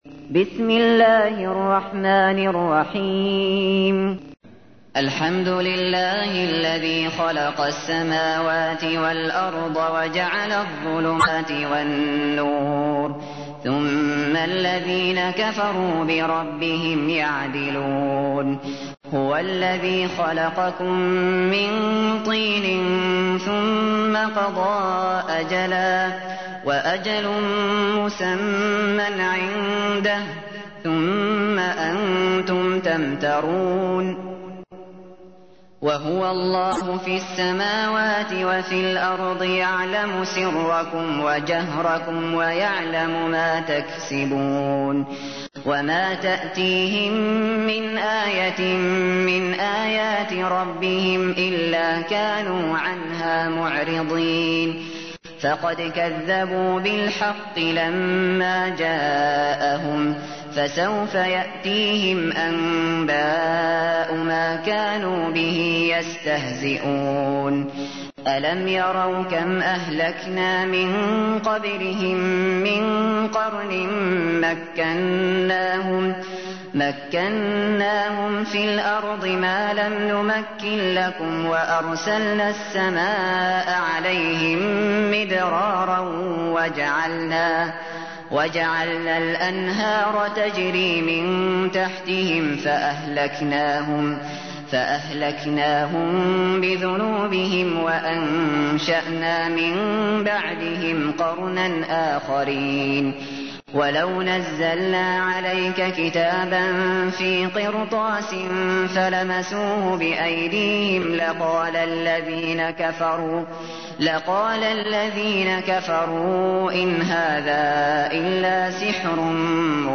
تحميل : 6. سورة الأنعام / القارئ الشاطري / القرآن الكريم / موقع يا حسين